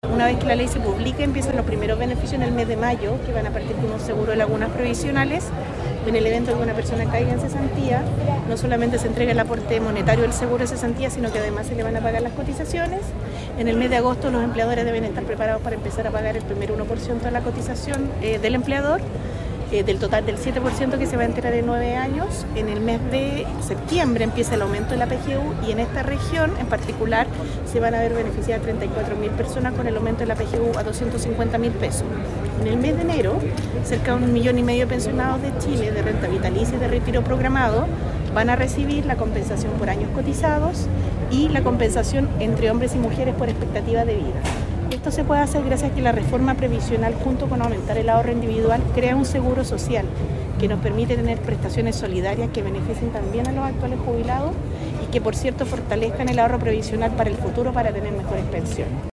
Este jueves se realizó un encuentro ciudadano en Concepción, en el contexto de la reciente promulgación de la Ley de Reforma de Pensiones, con la participación de la ministra del Trabajo y Previsión Social, Jeannette Jara, junto a otras figuras políticas de la región.